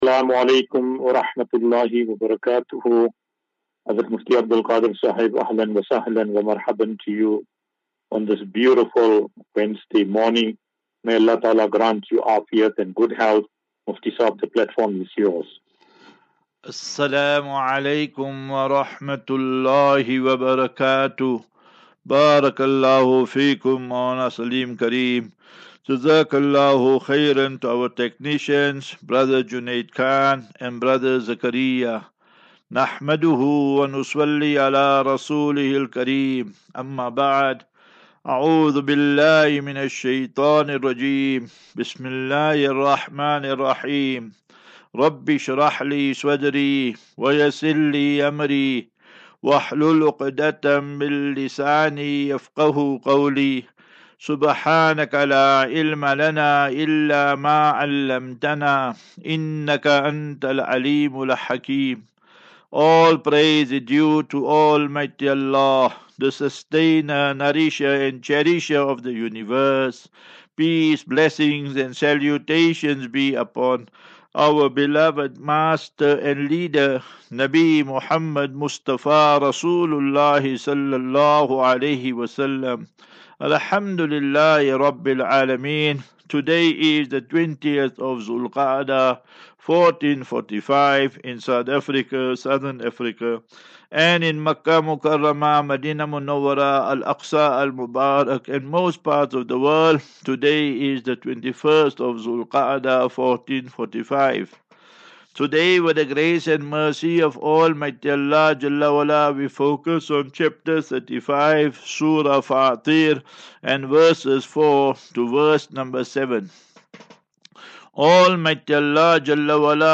QnA